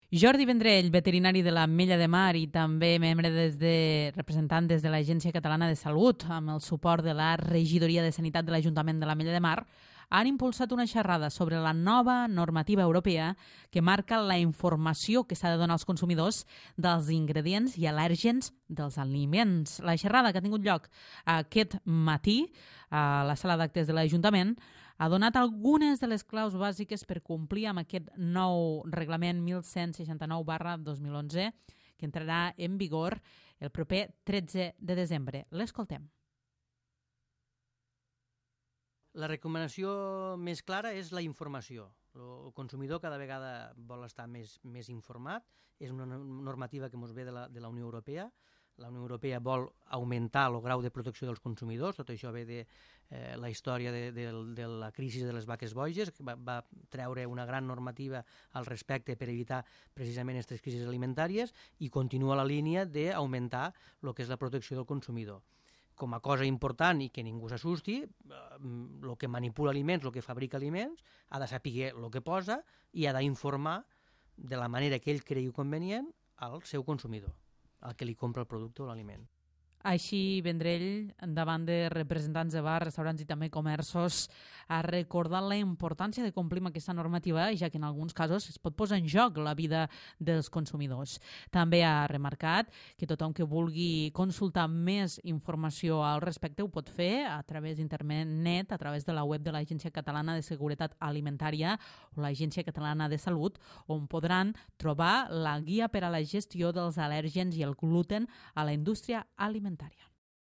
La xerrada que ha tingut lloc a la sala d'actes de l'Ajuntament ha donat algunes de les claus bàsiques per complir amb aquest nou reglament europeu 1169/2011 que entrarà en vigor el 13 de desembre.